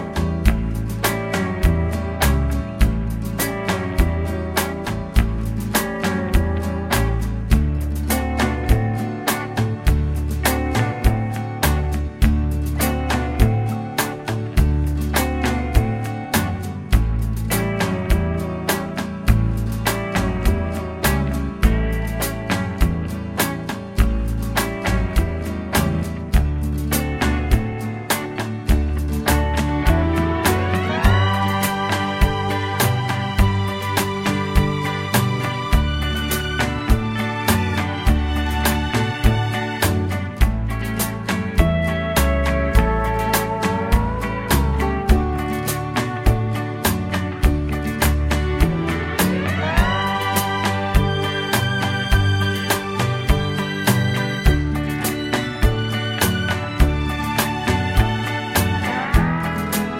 no Backing Vocals Crooners 2:50 Buy £1.50